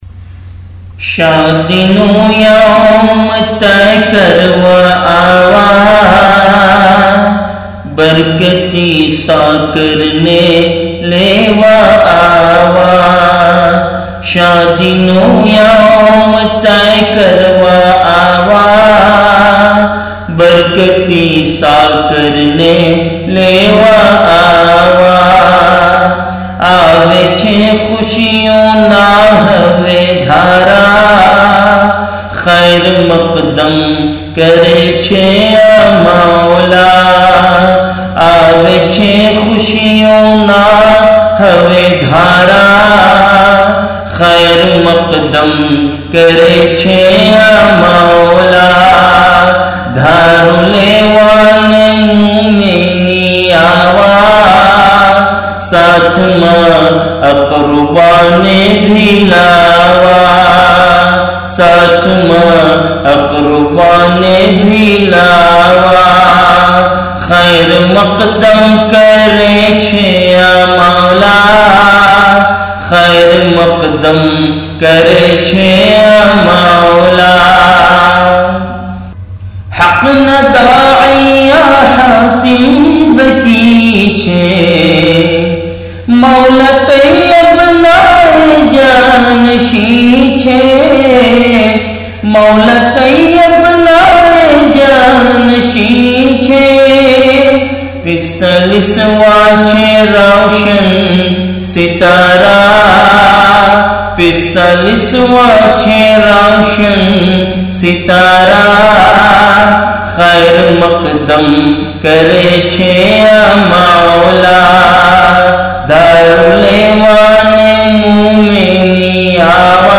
Special Ash'aar of Rasm-e-Dhaaru recited in the Majlis by Hudood-e-Da'wat - Audio